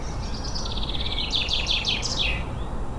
Buchfink
Buchfink – Stimme/Gesang:
Buchfinkenruf.ogg